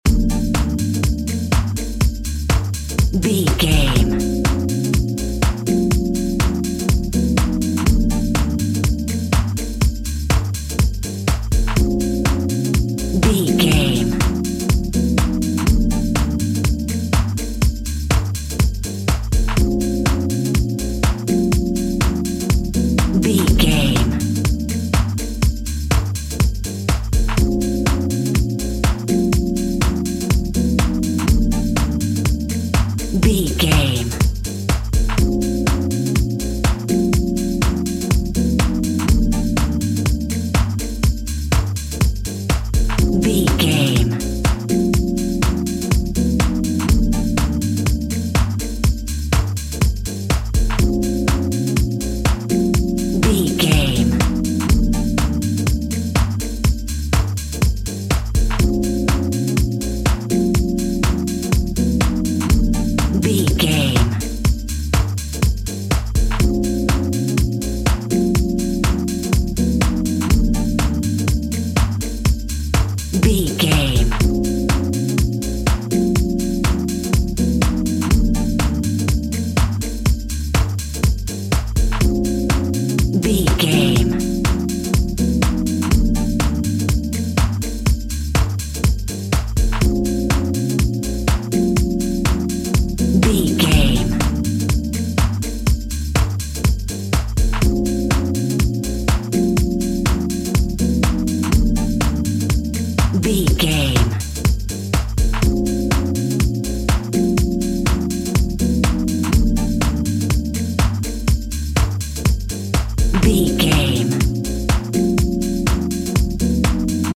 On Hold Music Theme.
D
groovy
futuristic
hypnotic
drum machine
electric piano
Lounge
chill out
laid back
nu jazz
downtempo
synth drums
synth leads
synth bass